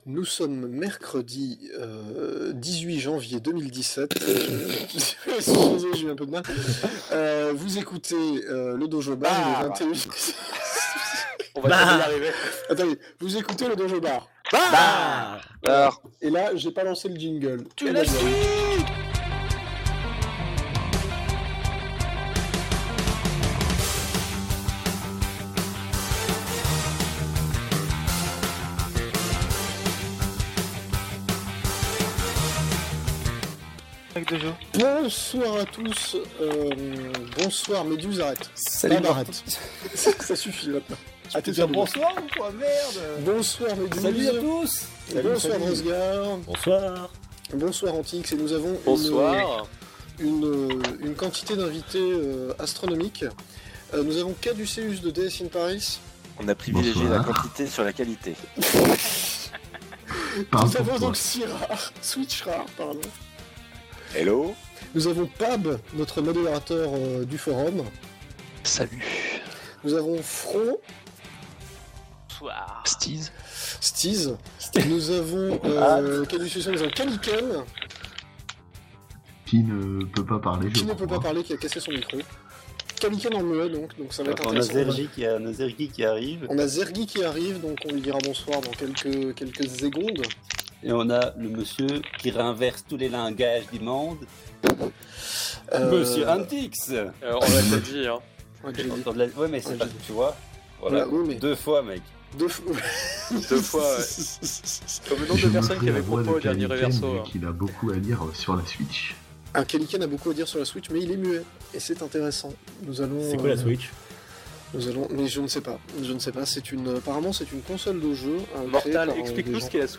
Tout d'abord, je souhaite m'excuser auprès de tout le monde : mon micro avait une petite tendance à saturer et je ne m'en suis pas rendu compte immédiatement.